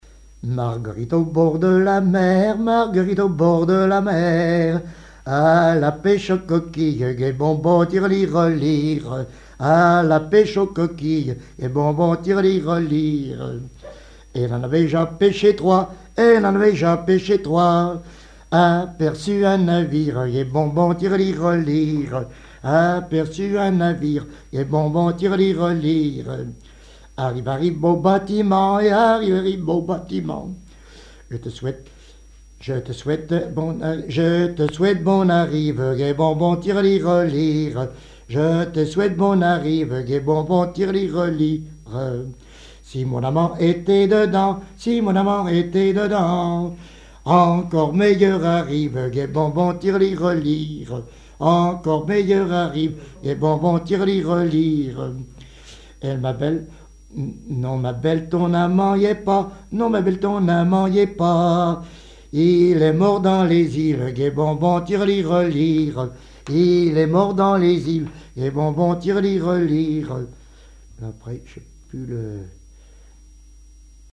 Genre laisse
Chansons populaires et témoignages
Pièce musicale inédite